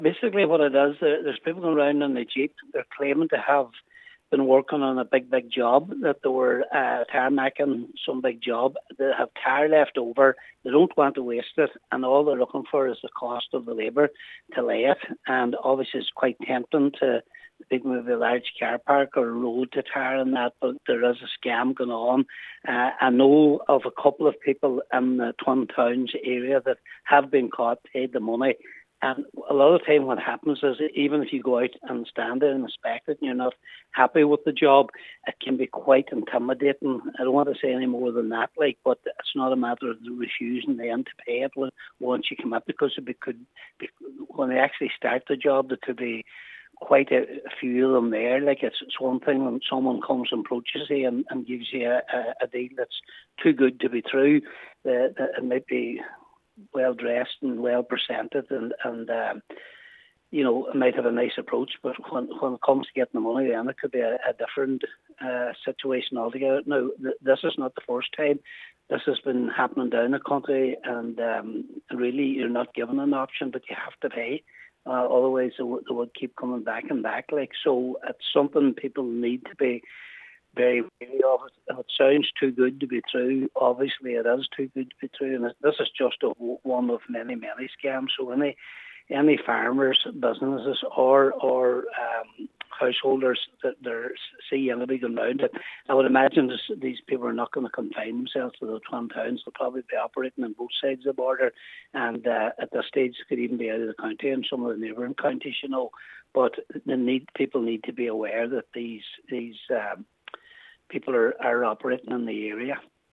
Councillor Patrick McGowan says the group is highly mobile: